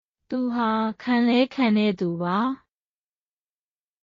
トゥ　ハ　カンレーカンテェ　トゥバ
当記事で使用された音声（日本語およびミャンマー語）はGoogle翻訳　および　Microsoft Translatorから引用しております。